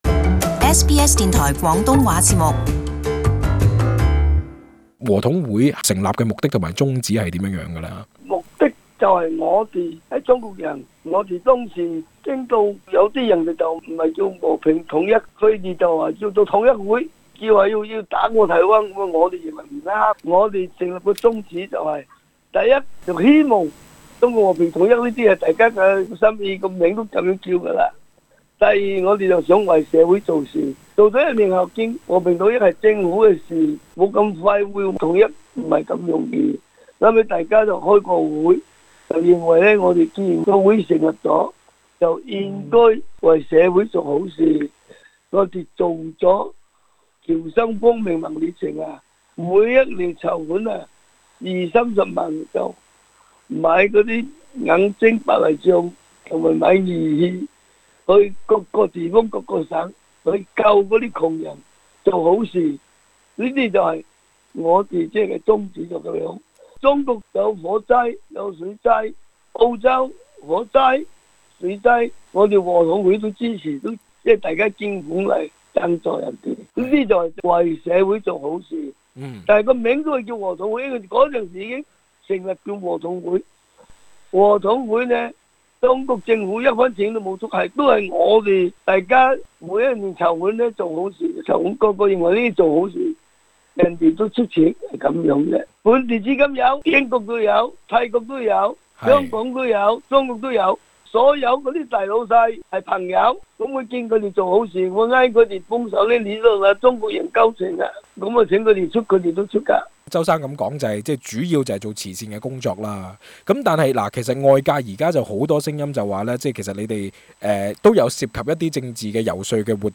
【時事專訪】澳洲和統會是否政治組織？